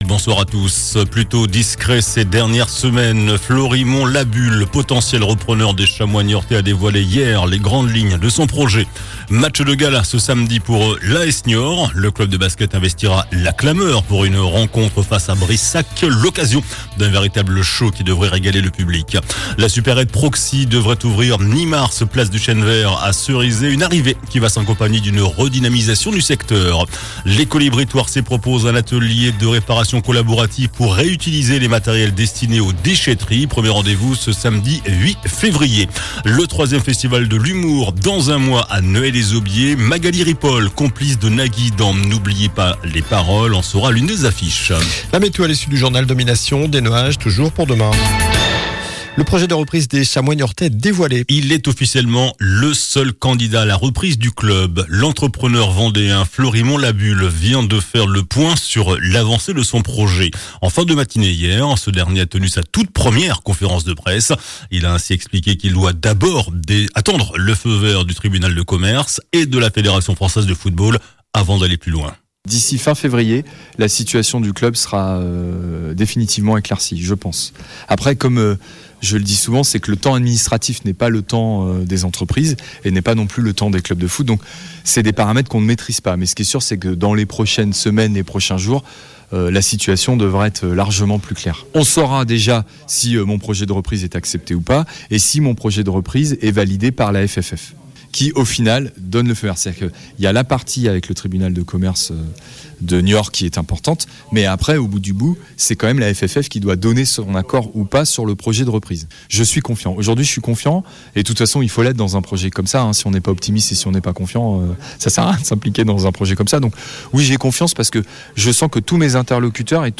JOURNAL DU JEUDI 06 FEVRIER ( SOIR )